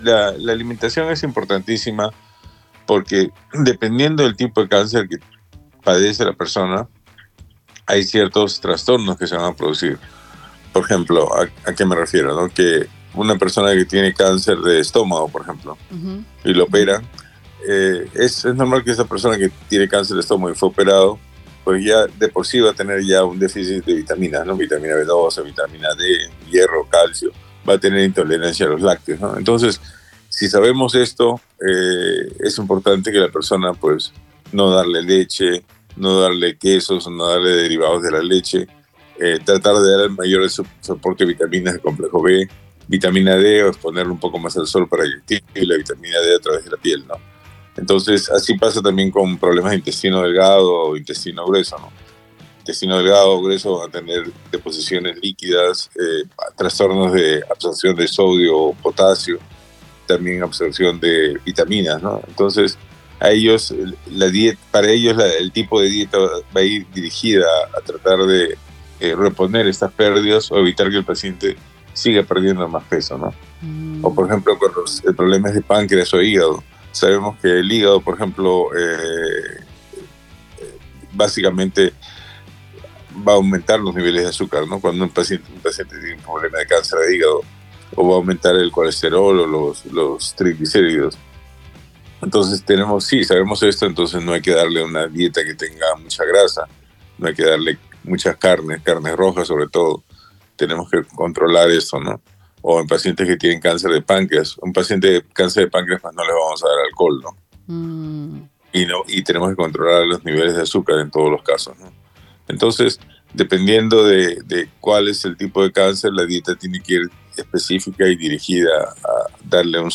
Hablando en el «Show de los Lunáticos» de Súper Tokio Radio